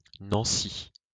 ^ /ˈnænsi/ NAN-see, UK also /ˈnɒ̃si/, US also /nɒ̃ˈs, ˈnɑːnsi/ NAHN-see,[3][4][5] French: [nɑ̃si]